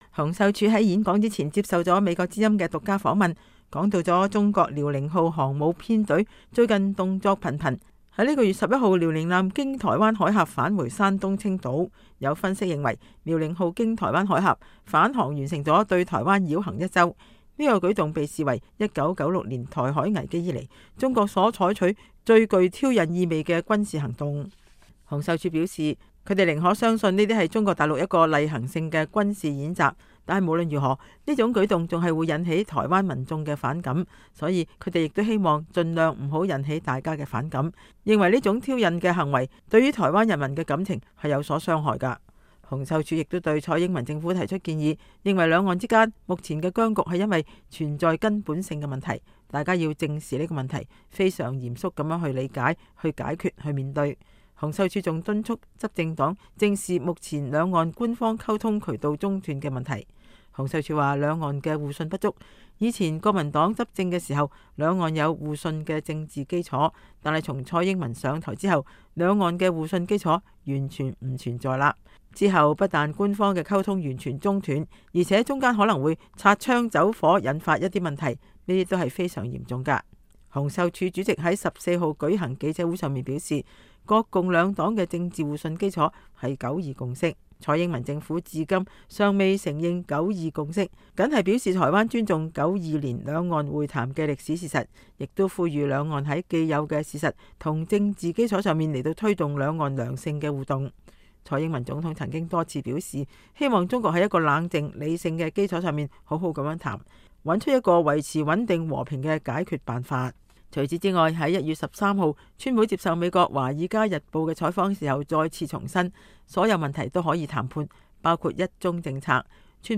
國民黨主席洪秀柱1月15日下午在舊金山下榻酒店舉行“團結救黨募款大會演講”，吸引了上百位舊金山灣區國民黨海外支持著前來捧場參加。洪秀柱在演說前接受了美國之音獨家訪問，談到中國遼寧號航母編隊最近動作頻頻，本月11日遼寧艦經台灣海峽返回山東青島